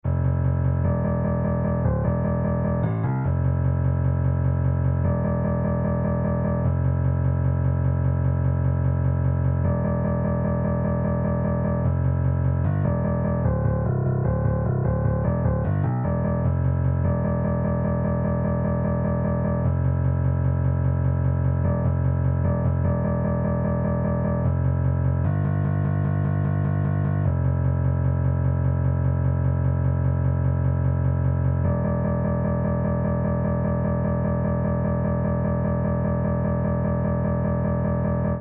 Mínimo: -0,7 Máximo: 0,9 instrumento: Piano Key: C Major Scale Range: 1 Octaves Track Tempo: 1x